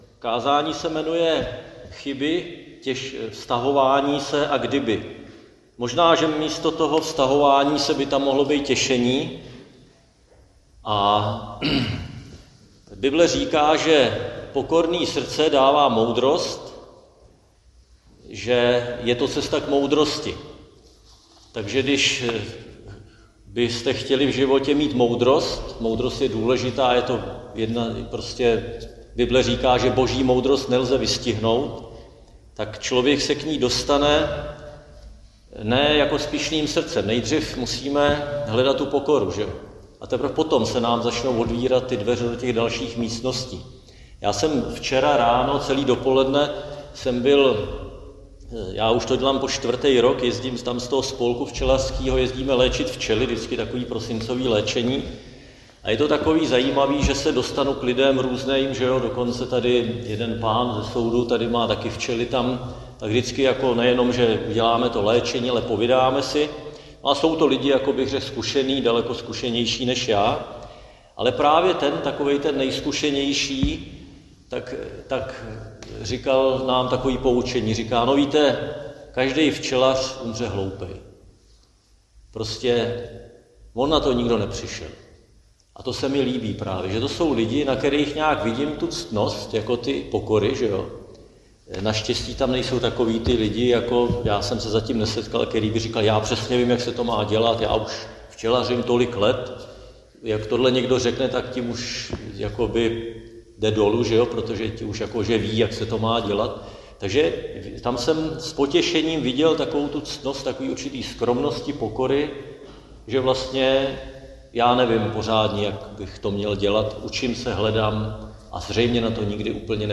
Křesťanské společenství Jičín - Kázání 30.11.2025